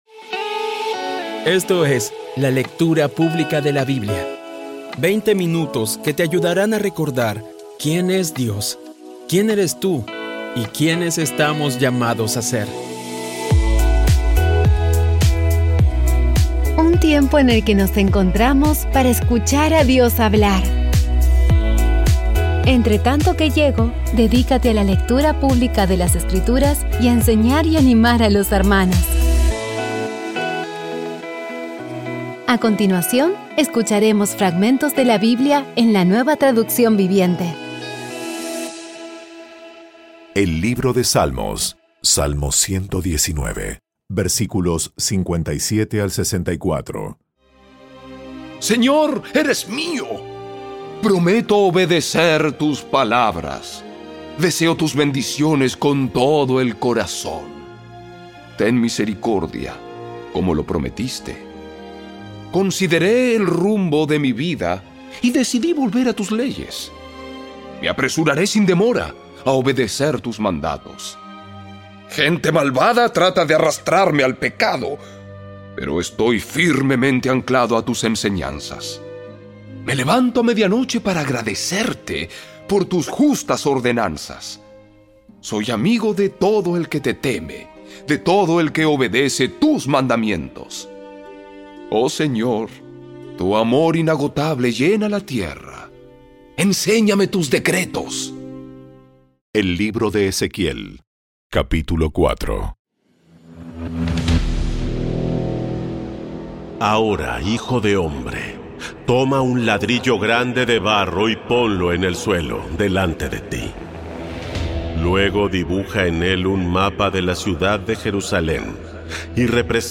Audio Biblia Dramatizada Episodio 303
Poco a poco y con las maravillosas voces actuadas de los protagonistas vas degustando las palabras de esa guía que Dios nos dio.